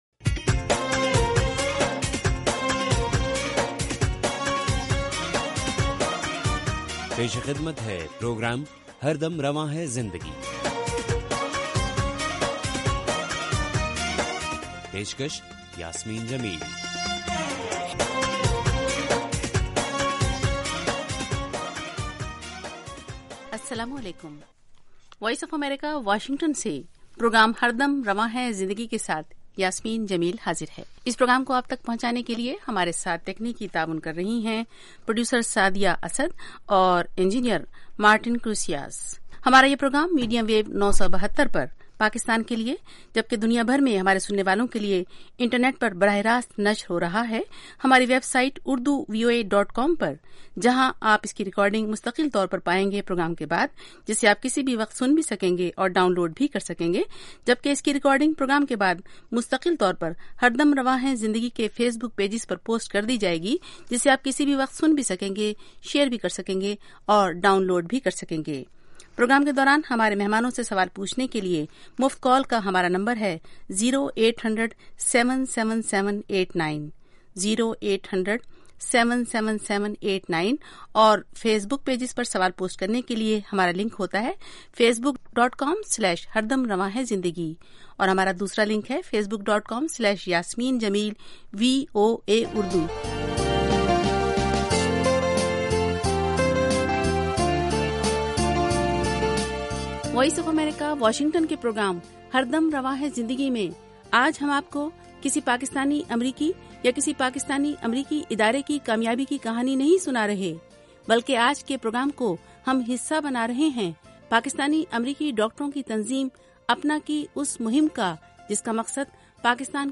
اس ایک گھنٹے کے پروگرام میں دن بھر کی اہم خبریں اور پاکستان اور بھارت سے ہمارے نمائندوں کی رپورٹیں پیش کی جاتی ہیں۔ اس کے علاوہ انٹرویو، صحت، ادب و فن، کھیل، سائنس اور ٹیکنالوجی اور دوسرے موضوعات کا احاطہ کیا جاتا ہے۔